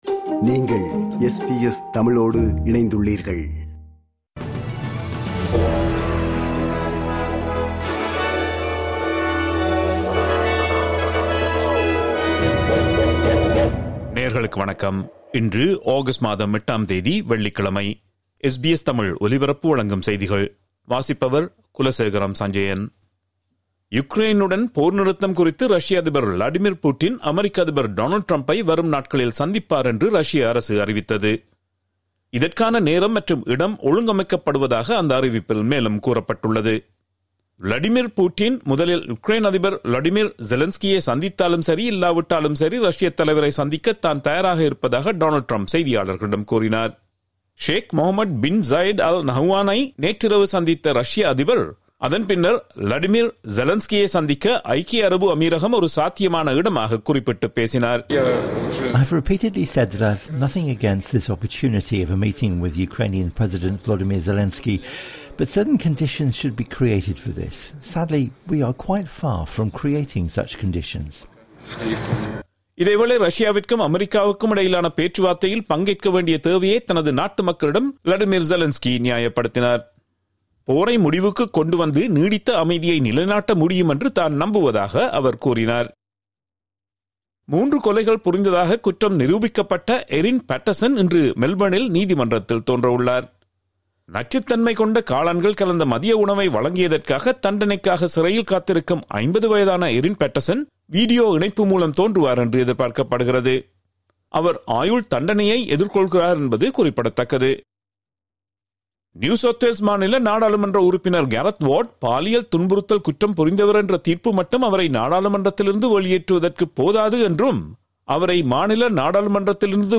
SBS தமிழ் ஒலிபரப்பின் இன்றைய (வெள்ளிக்கிழமை 08/08/2025) செய்திகள்.